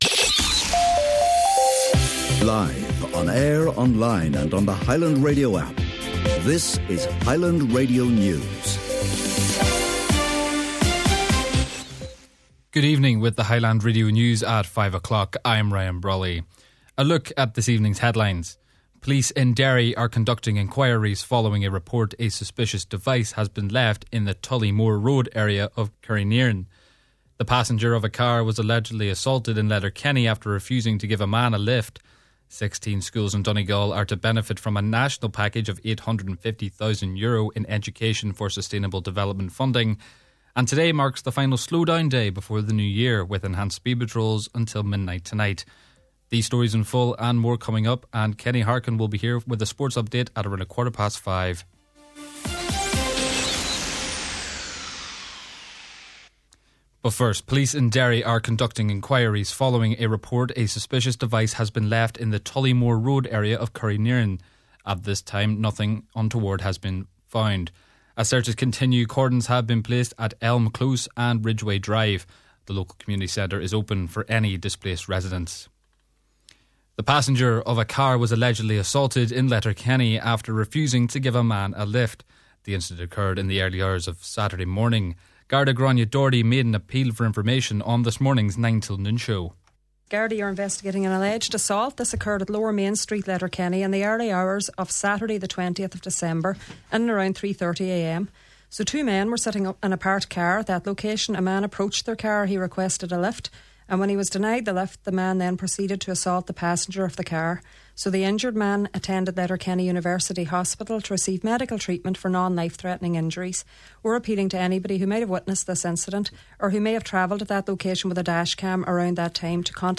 Main Evening News, Sport & Obituary Notices – Tuesday December 23rd